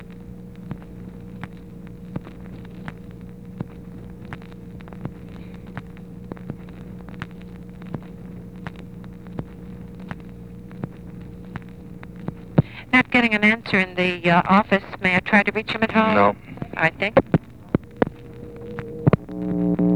Conversation with TELEPHONE OPERATOR, June 11, 1964
Secret White House Tapes